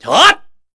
Ezekiel-Vox_Attack2_kr.wav